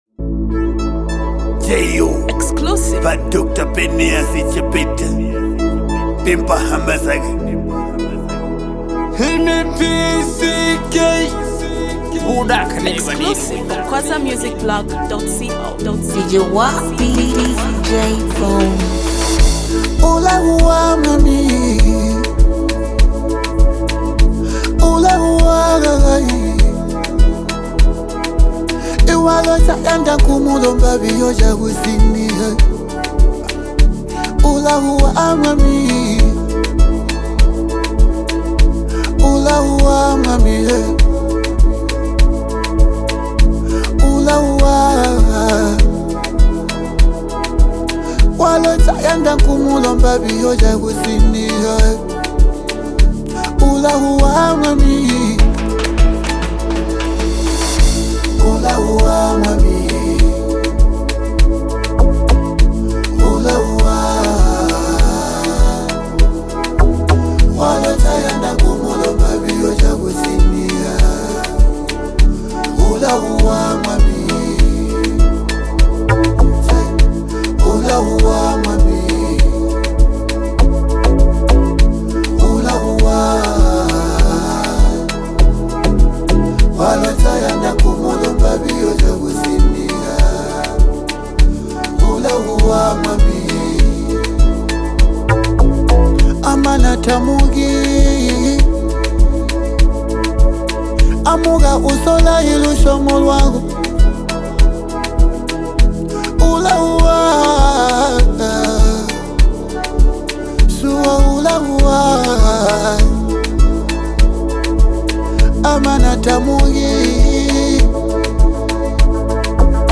authentic Cuundu Vibe